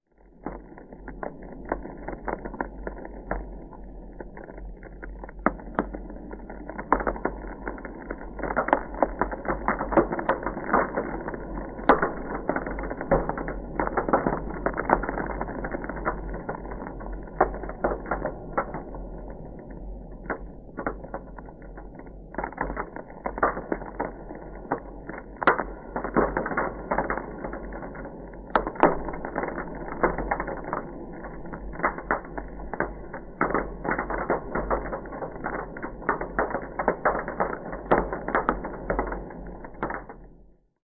Звуки треска льда
Треск ломающегося льда на реке